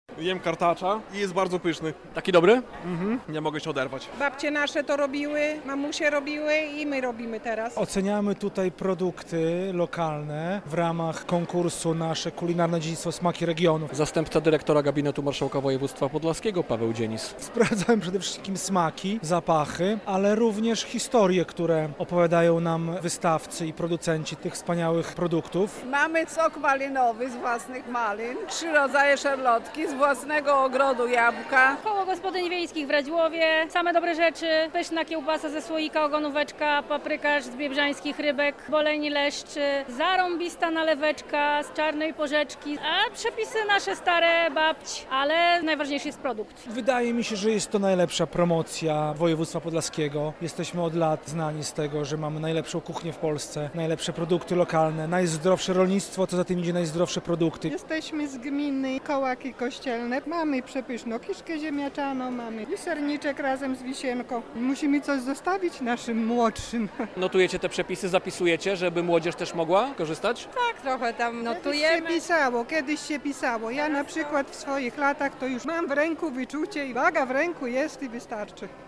Kilkadziesiąt produktów regionalnych przygotowali producenci i koła gospodyń wiejskich z całego woj. podlaskiego. W ramach kolejnej edycji konkursu "Nasze Kulinarne Dziedzictwo – Smaki Regionów" prezentują je w Marianowie.
"Nasze Kulinarne Dziedzictwo – Smaki Regionów" - relacja